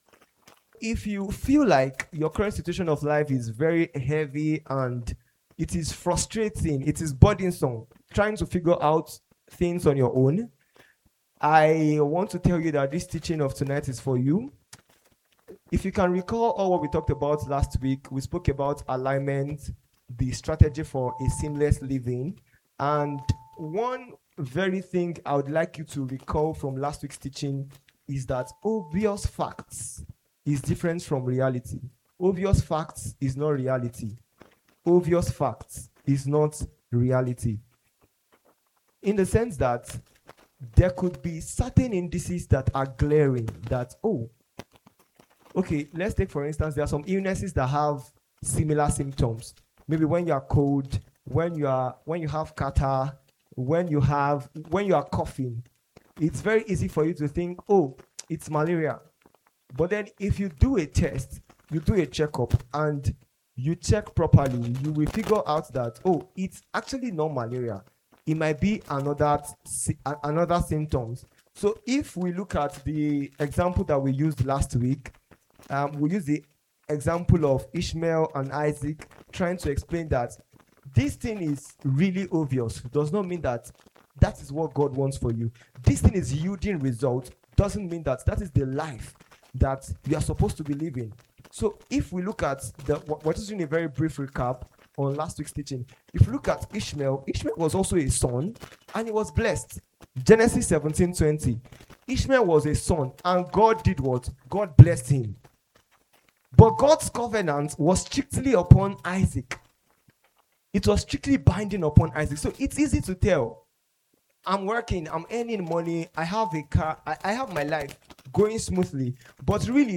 Service Type: Wednesday Service